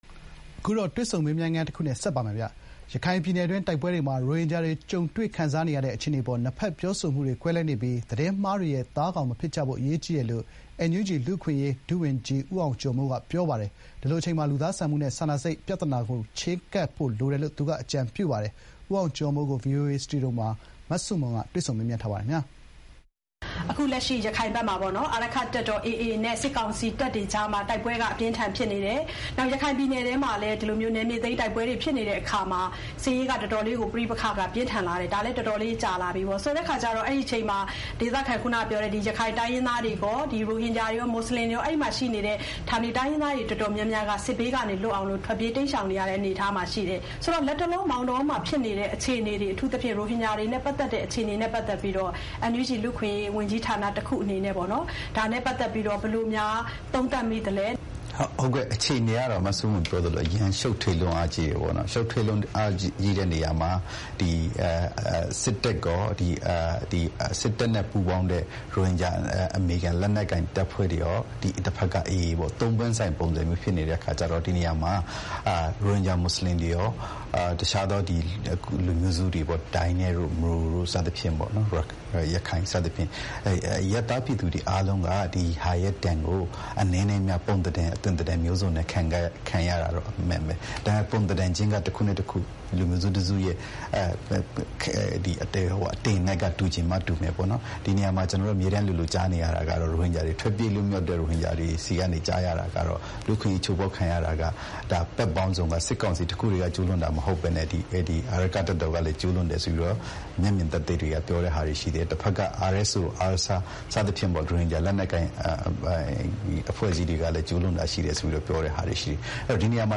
ဗွီအိုအေ စတူရီယိုမှာ